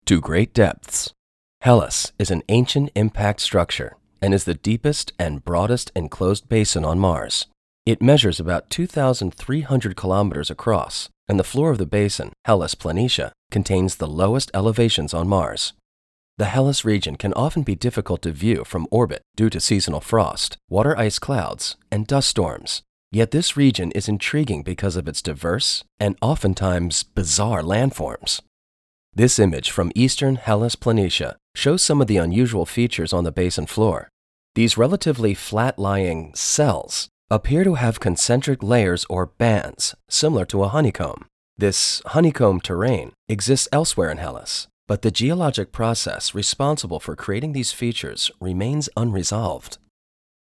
narration